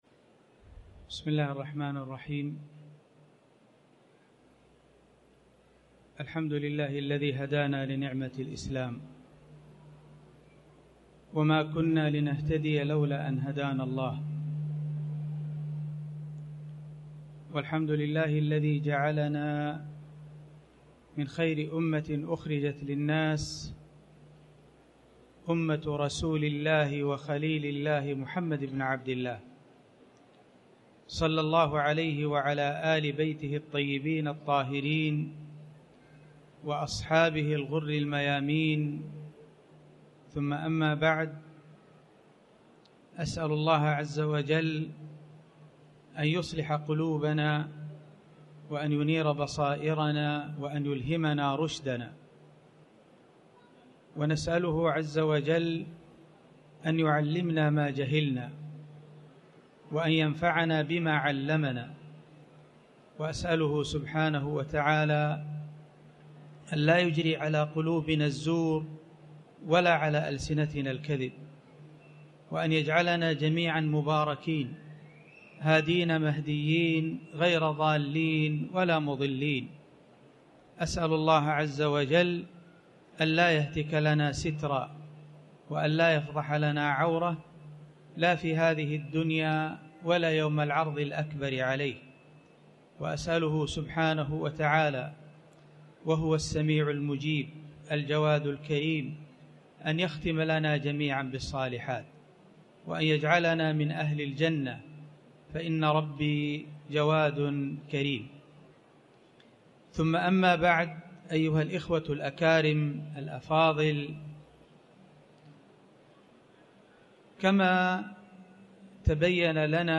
تاريخ النشر ٢٧ صفر ١٤٤٠ هـ المكان: المسجد الحرام الشيخ